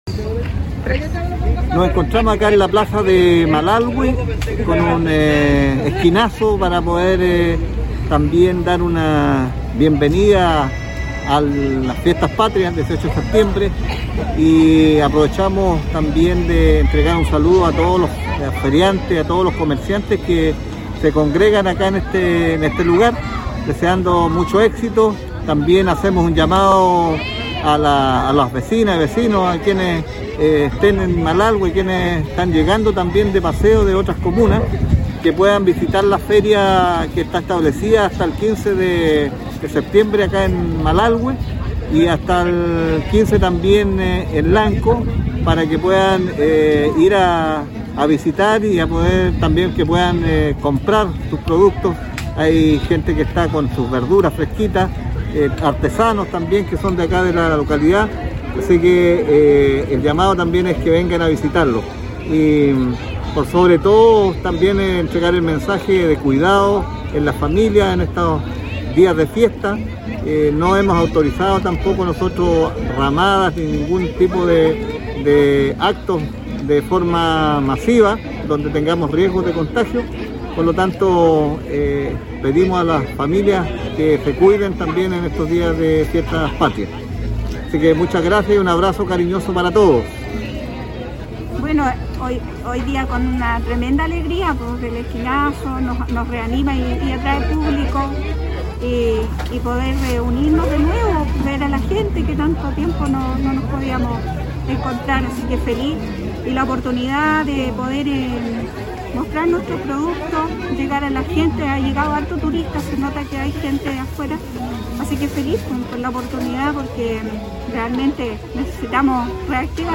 En medio de la Feria del Pago de Jubilados y Pensionados de Malalhue, la Ilustre Municipalidad de Lanco realizó un esquinazo folclórico, para continuar con las actividades de Fiestas Patrias en la localidad.